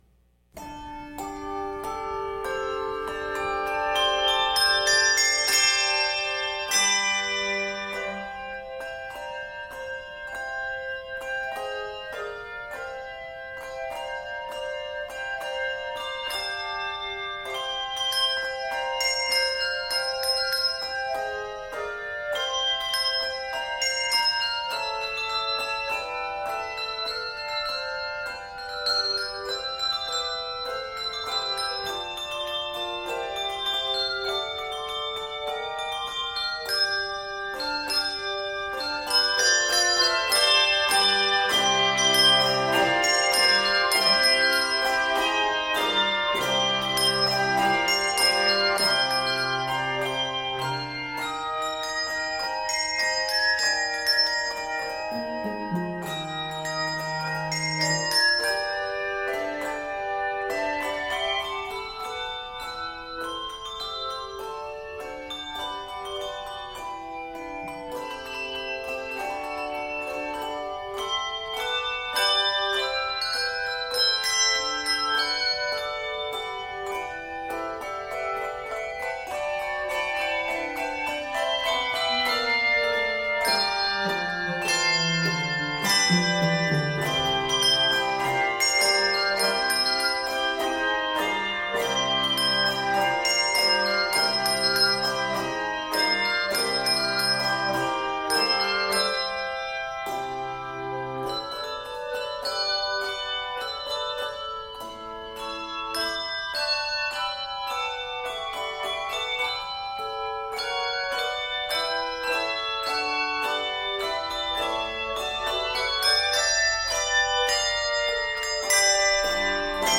N/A Octaves: 3-5 Level